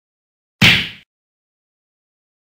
دانلود آهنگ مشت زدن 1 از افکت صوتی انسان و موجودات زنده
دانلود صدای مشت زدن 1 از ساعد نیوز با لینک مستقیم و کیفیت بالا
جلوه های صوتی